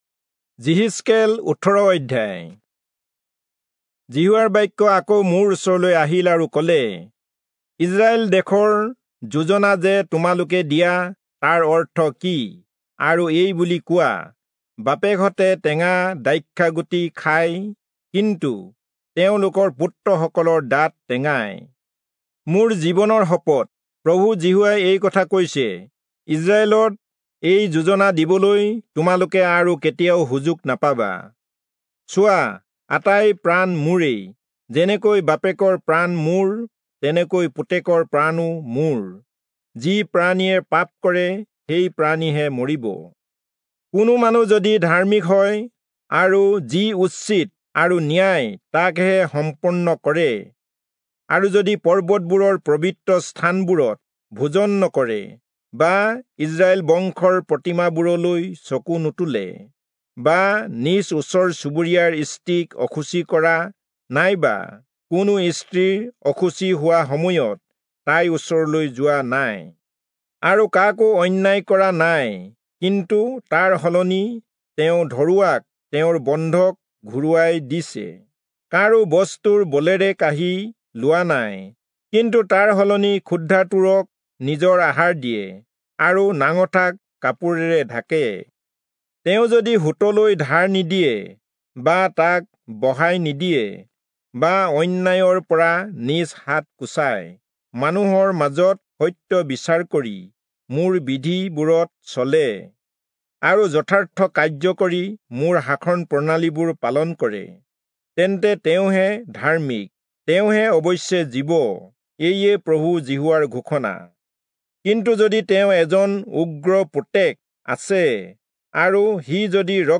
Assamese Audio Bible - Ezekiel 20 in Ocvkn bible version